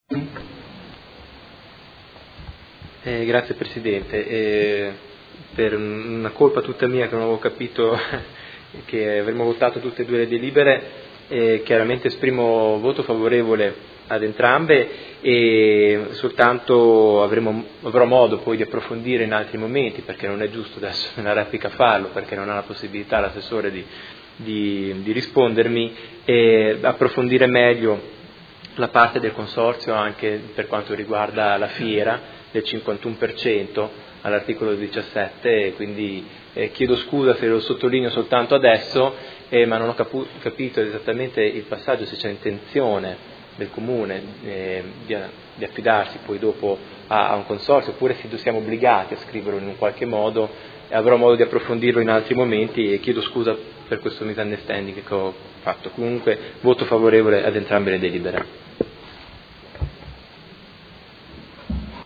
Marco Chincarini — Sito Audio Consiglio Comunale
Seduta del 20/07/2017 Dichiarazione di voto. Delibera: Regolamento Mercato Coperto Albinelli – Approvazione modifiche e Delibera: Regolamento della Fiera di S. Antonio e San Geminiano – Approvazione modifiche